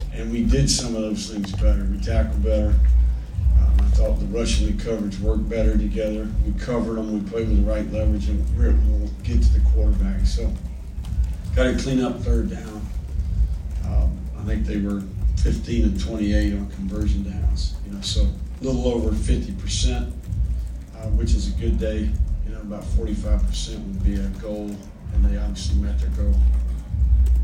Florida coach Billy Napier said the improvement defensively from the last game was key: